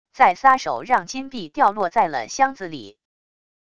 再撒手让金币掉落在了箱子里wav音频